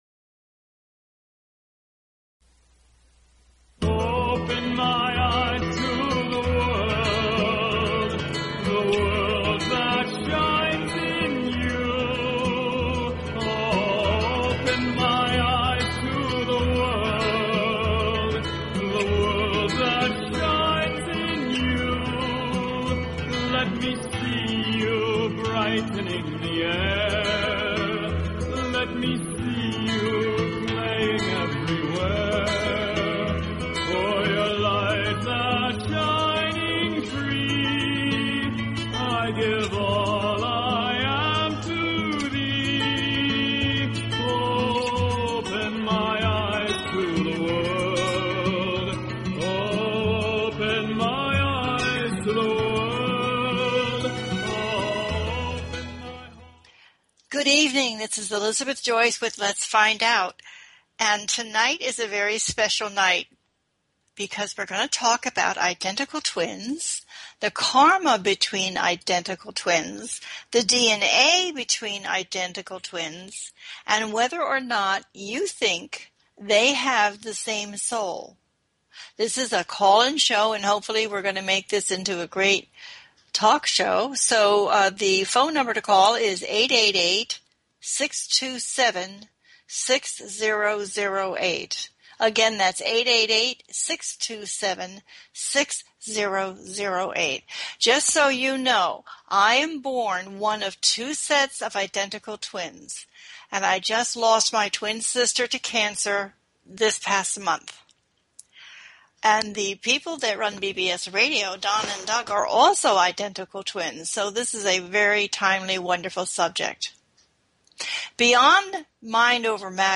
Talk Show Episode, Audio Podcast, IDENTICAL TWINS UNIVERSE